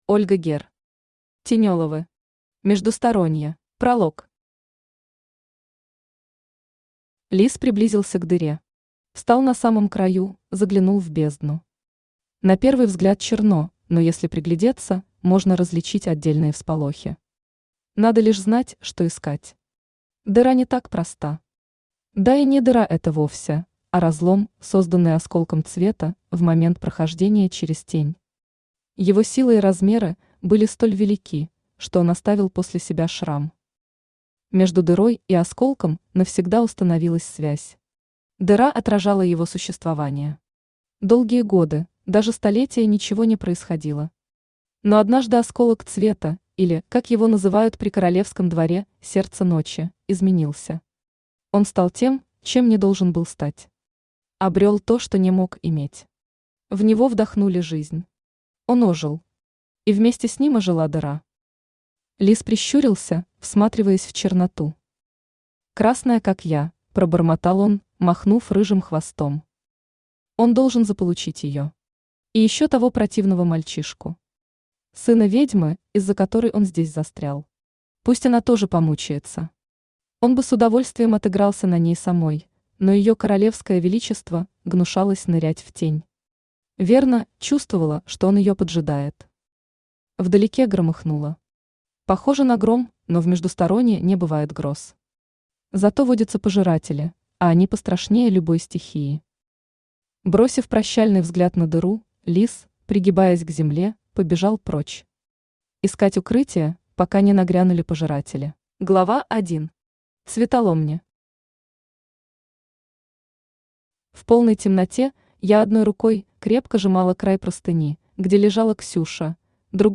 Аудиокнига Тенеловы. Междусторонье | Библиотека аудиокниг
Междусторонье Автор Ольга Герр Читает аудиокнигу Авточтец ЛитРес.